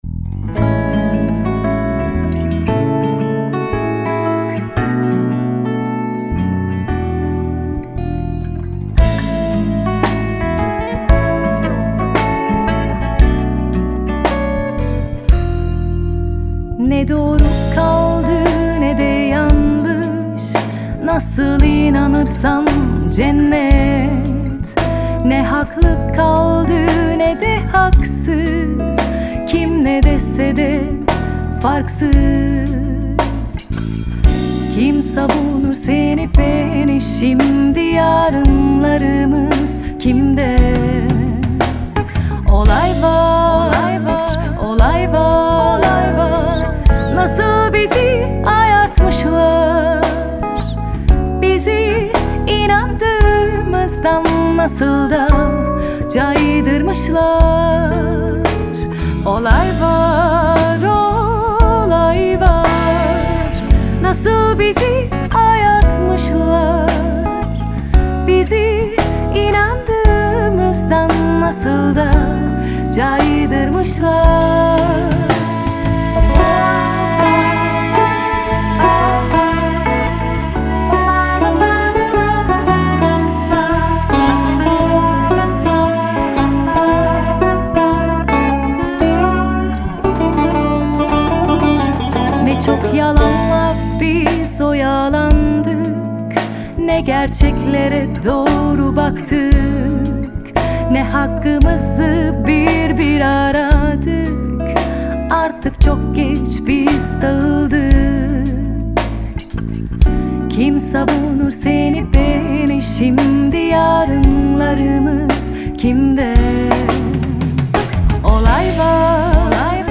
Türkisch Folk-Pop,
Alternative, Pop Jazz